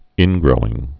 (ĭngrōĭng)